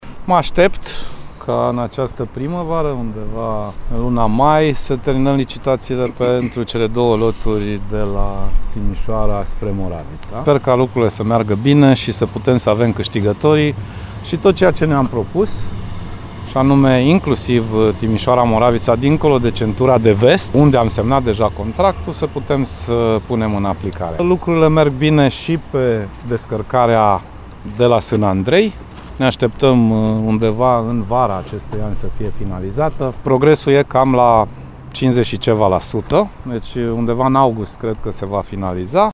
Licitația pentru cele două loturi ale autostrăzii Timișoara – Moravița ar putea fi finalizată în această primăvară, a anunțat, la Timișoara, ministrul Transporturilor, Sorin Grindeanu.